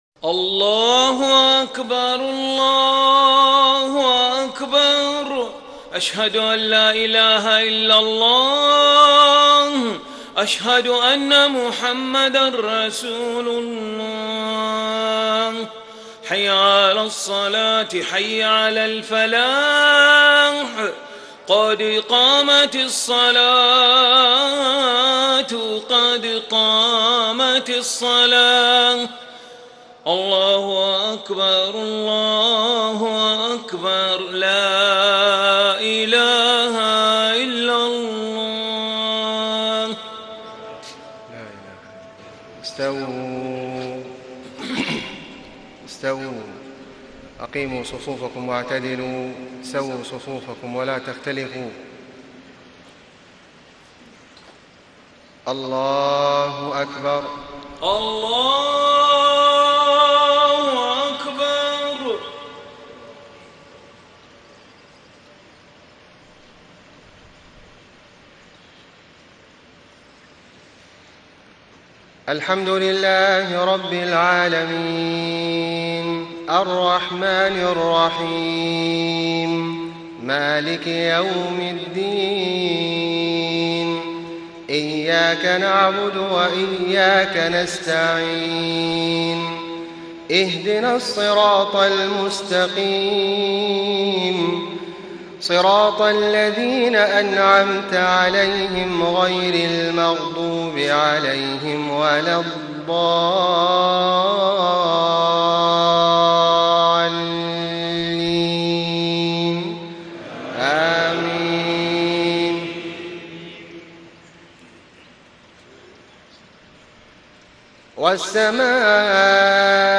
صلاة المغرب 9-7-1434هـ سورة البروج > 1434 🕋 > الفروض - تلاوات الحرمين